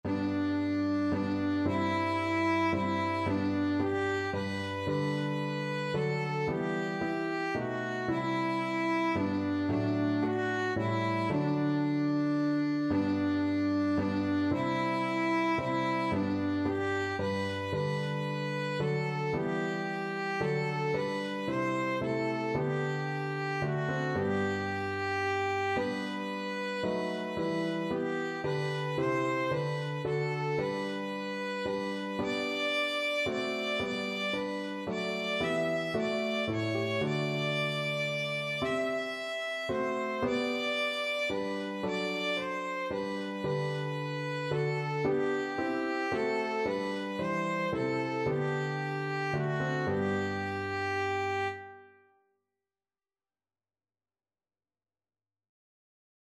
Christian
3/4 (View more 3/4 Music)
Classical (View more Classical Violin Music)